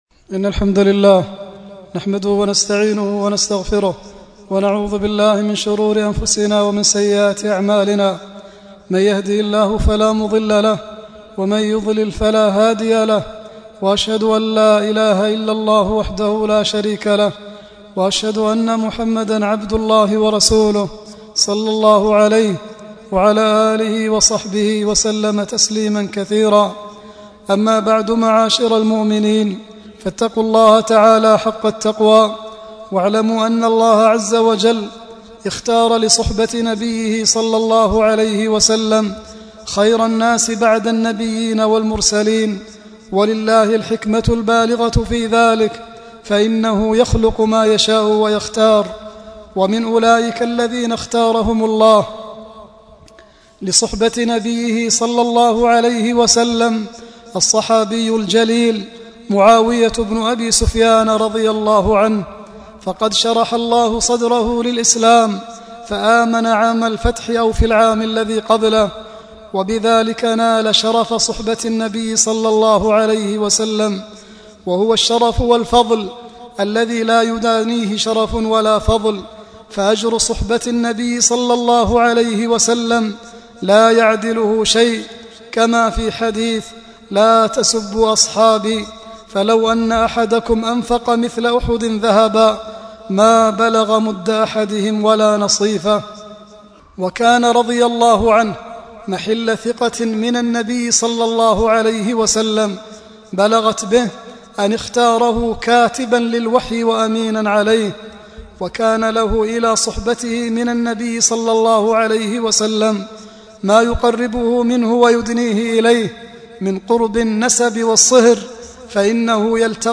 العنوان : خطبة مختصرة في فضل معاوية رضي الله عنه